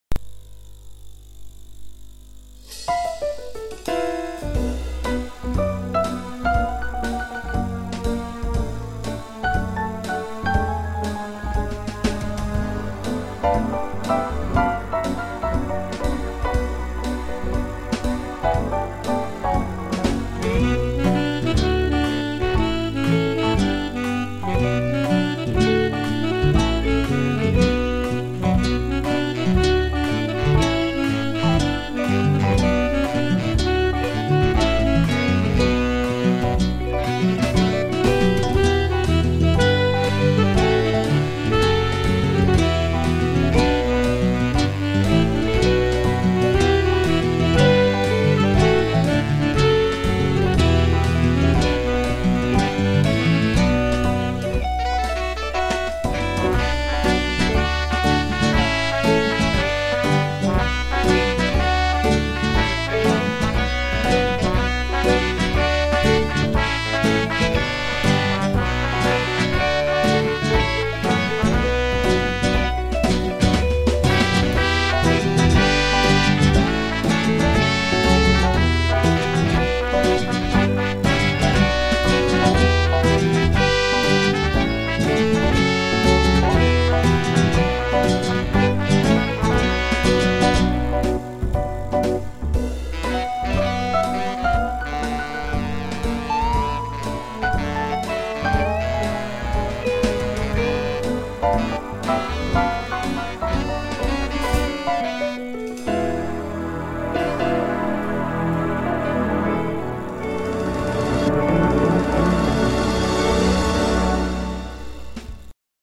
הסגנון של הביצוע מענין.
זה לא כמו הביצוע שהייתי מצפה לשיר הזה, רגוע, נינוח..
רק הבעיה במעברים. צורמים ולא חלקים.
שומעים טוב ונקי!